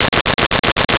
Arcade Movement 05.wav